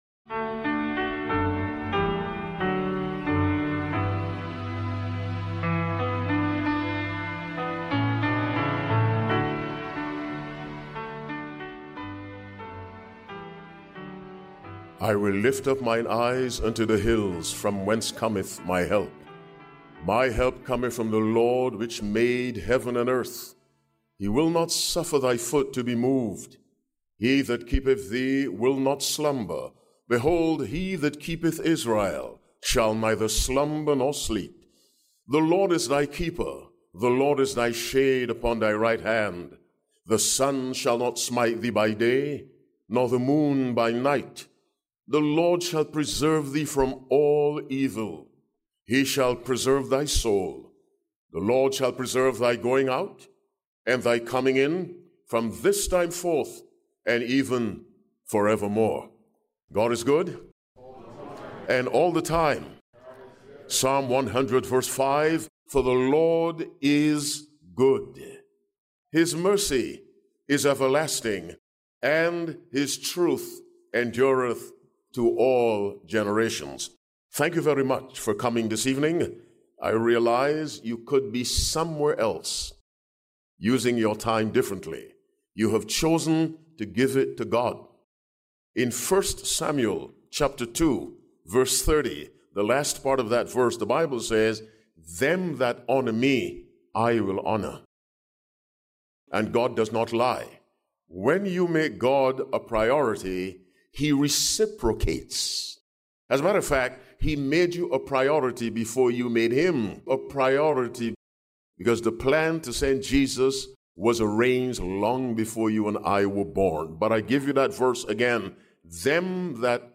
This sermon reveals how true peace comes from trusting God’s unchanging Word and resting in His constant protection. Through Scripture, powerful testimonies, and lessons on faith over fear, it reminds believers that lasting peace isn’t found in circumstances, but in the steadfast promises and sovereignty of God.